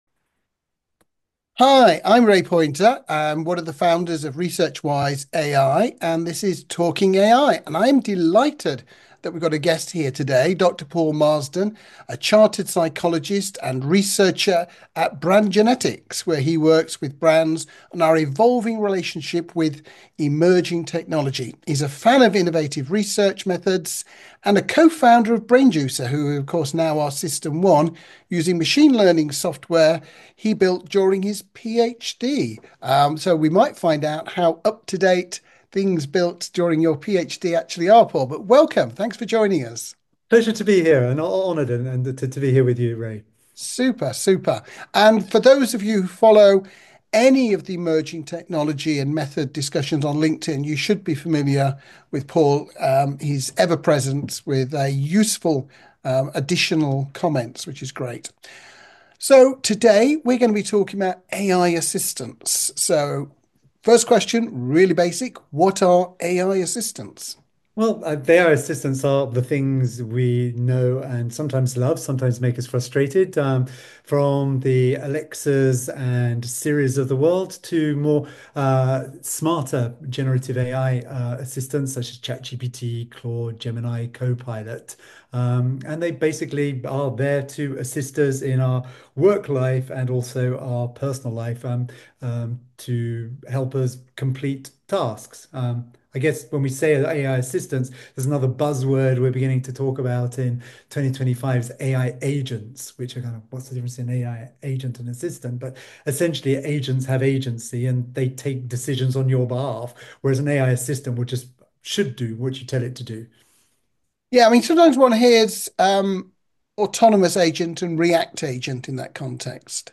Whether you're a tech enthusiast, business leader, or industry professional, this in-depth discussion provides valuable insights into the future of technology and innovation.